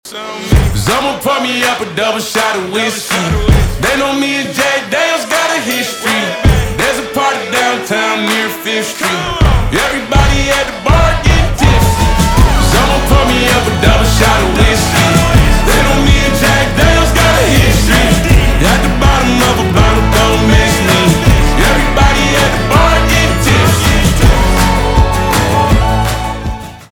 кантри
хлопки , гитара , скрипка